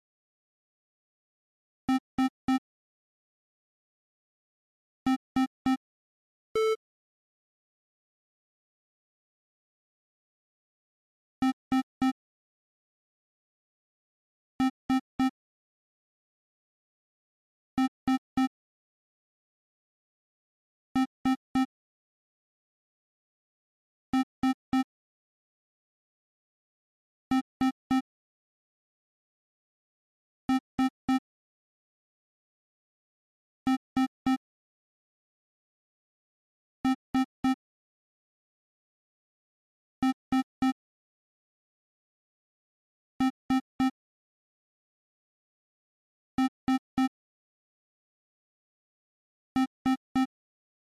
警报 " 警报2+NR
描述：Alarm2.wav降噪。此版本试图消除iPod的录音噪音。我的无线电控制投影闹钟大声哔哔叫醒你！开始慢然后加速直到我关闭它。还提供降噪版本。第四代iPod touch，使用media.io转换并使用Audacity编辑。
标签： 哔哔声 哔哔声 警告 长时间再次 哔哔声 之后 发出蜂鸣声 唤醒 蜂鸣声 早晨 闹钟 闹钟 时钟 电子 警告蜂鸣器
声道立体声